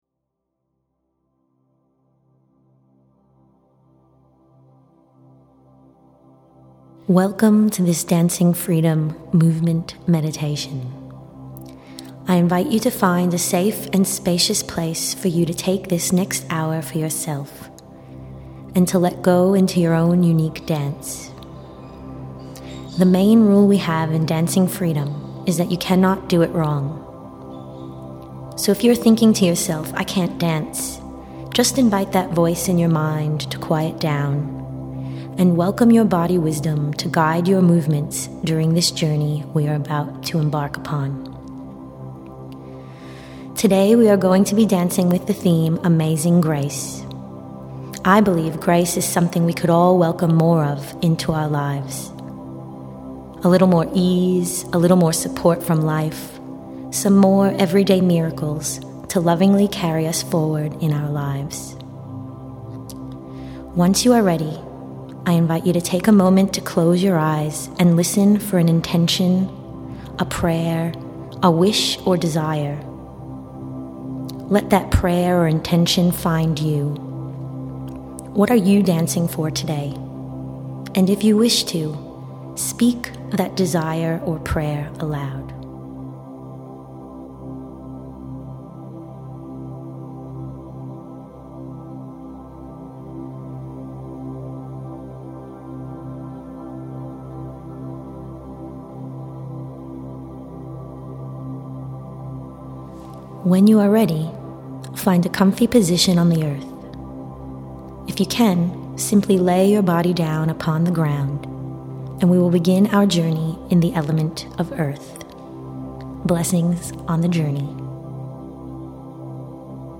Amazing Grace is a guided Dancing Freedom journey through the elements. It is a somatic, ecstatic and shamanic dance practice that supports the re-emergence of embodied wholeness.